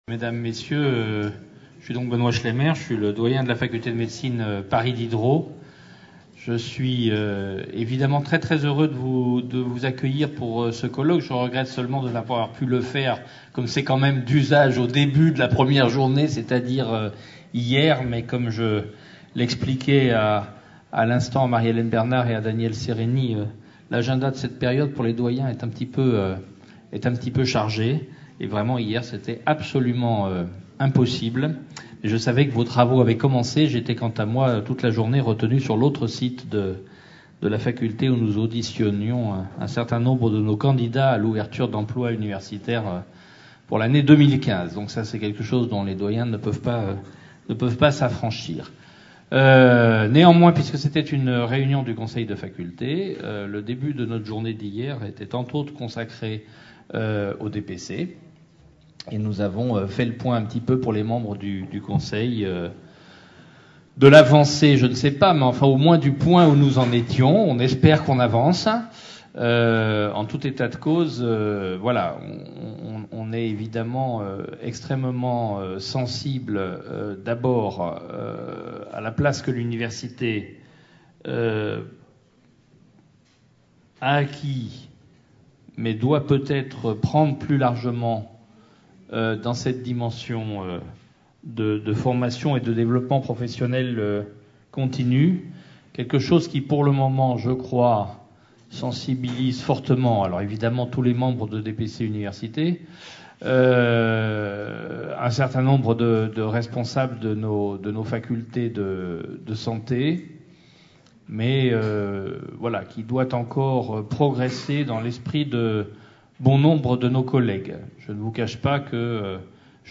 DPC-SU 2014 - Allocutions de bienvenue | Canal U
Conférence enregistrée lors du colloque DPC-SU le vendredi 6 juin 2014 à Paris.